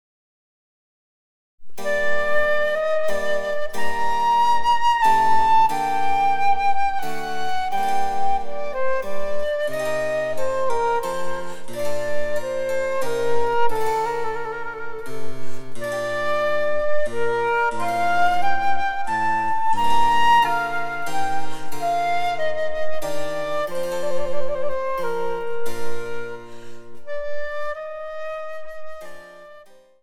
■フルートによる演奏
チェンバロ（電子楽器）